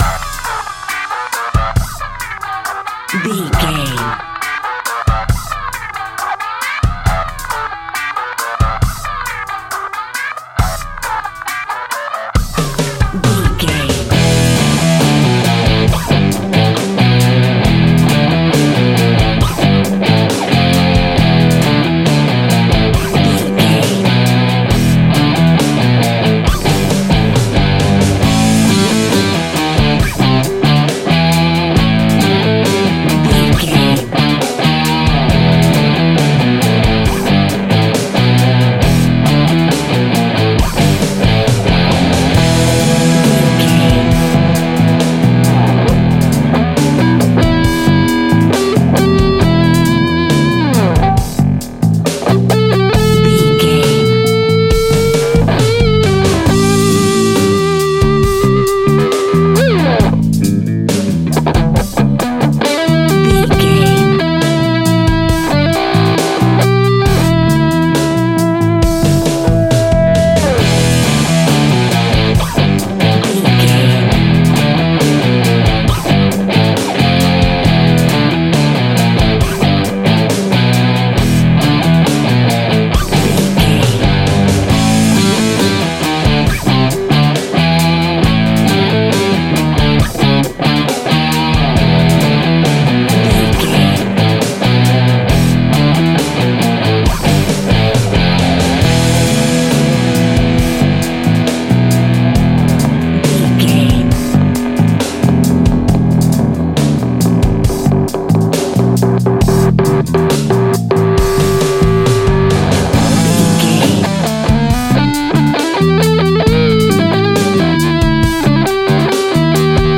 Epic / Action
Aeolian/Minor
hard rock
heavy metal
Heavy Metal Guitars
Metal Drums
Heavy Bass Guitars